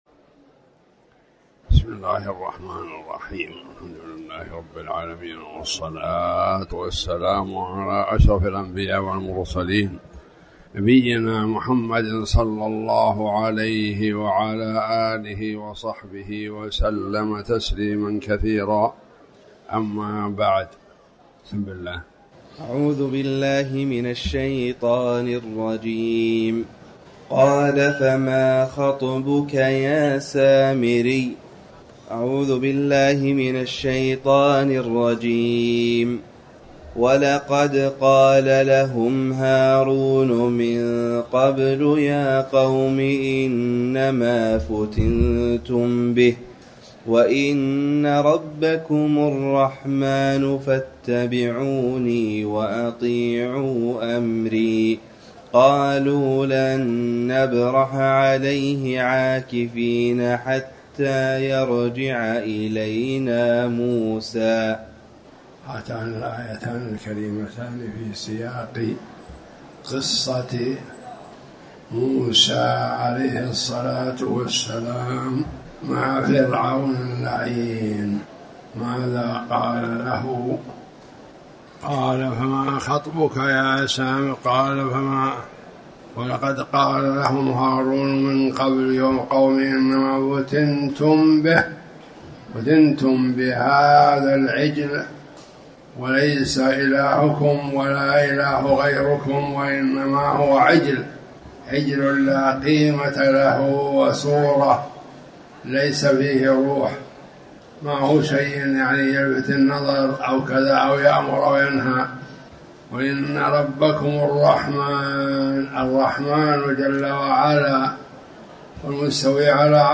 تاريخ النشر ٢٦ ربيع الأول ١٤٤٠ هـ المكان: المسجد الحرام الشيخ